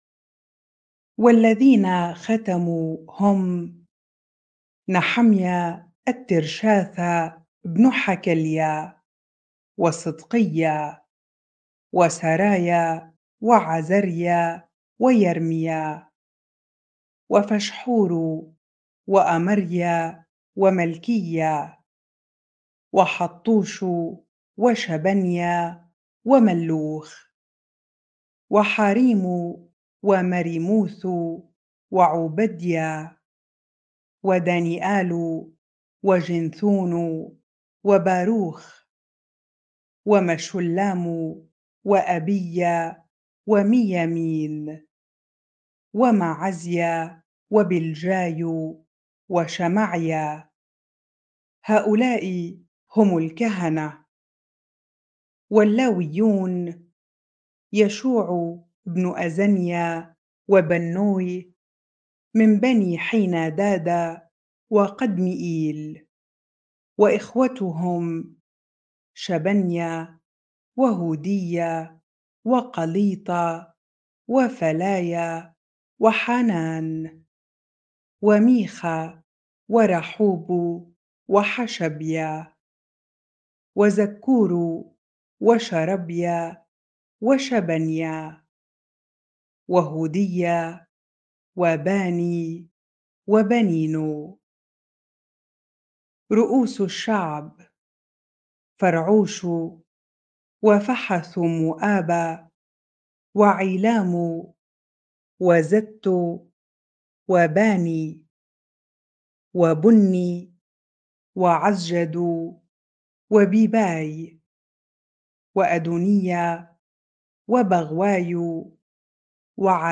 bible-reading-Nehemiah 10 ar